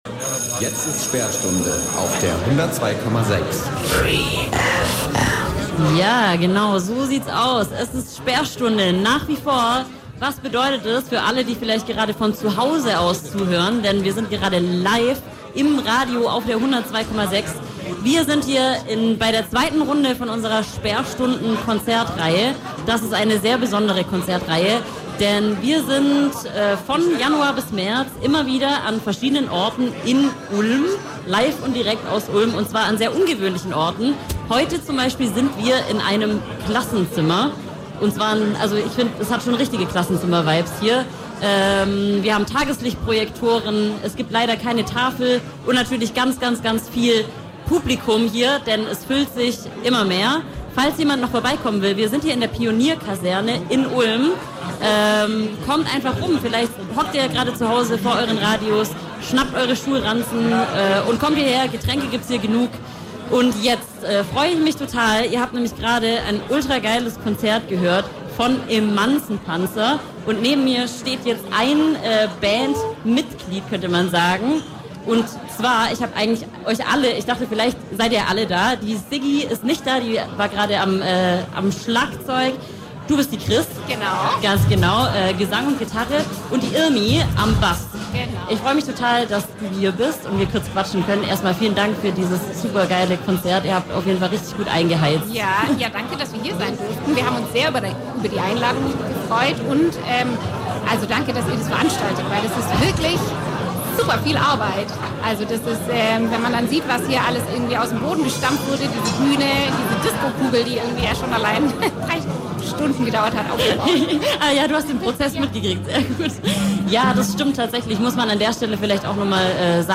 Pam Pam Ida im Interview